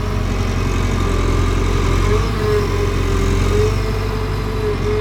CONSTRUCTION_Digger_Digging_01_loop_stereo.wav